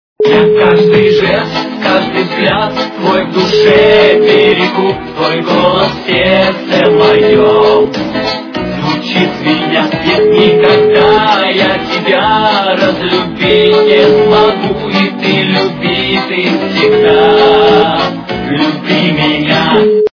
русская эстрада
При заказе вы получаете реалтон без искажений.